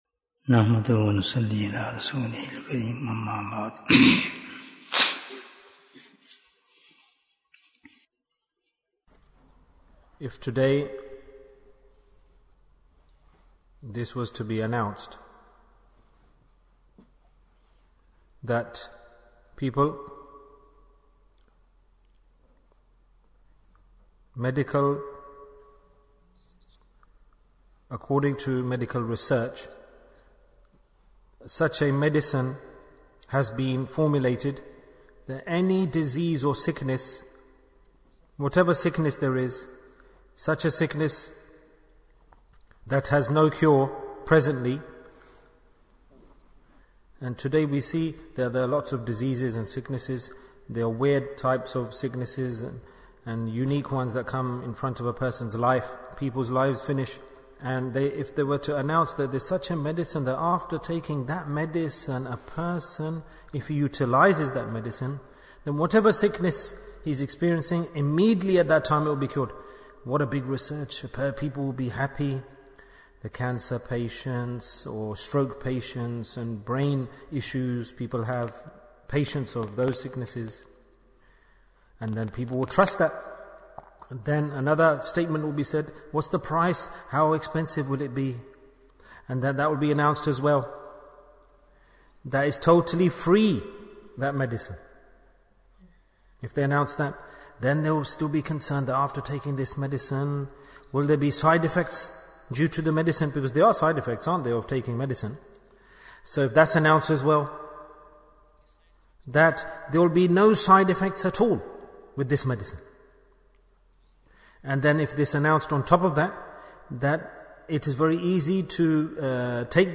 The Cure for Everything Bayan, 13 minutes23rd May, 2023